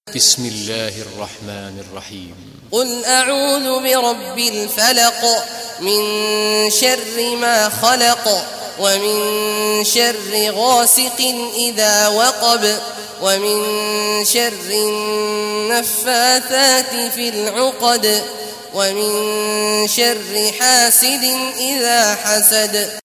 Surah Falaq Recitation by Sheikh Abdullah Juhany
Surah Falaq, listen or play online mp3 tilawat / recitation in Arabic in the beautiful voice of Imam Sheikh Awad al Juhany.